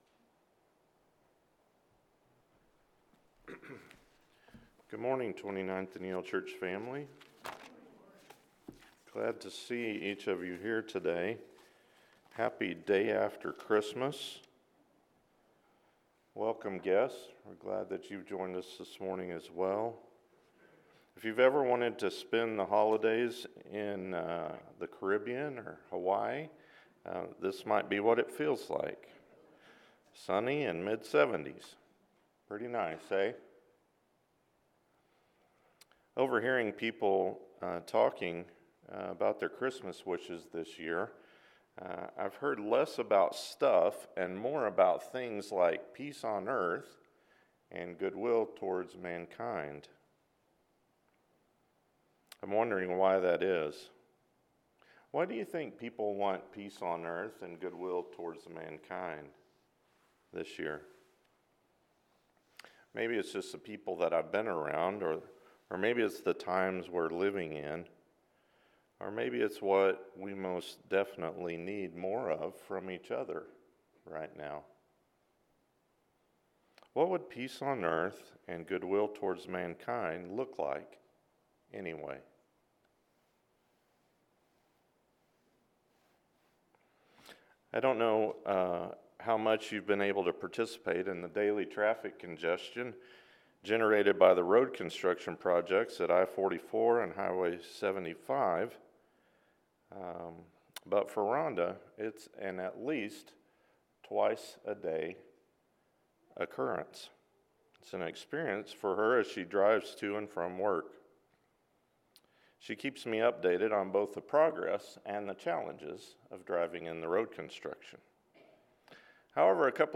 Kingdom Stories: The Good Samaritan – Luke 10:25-37 – Sermon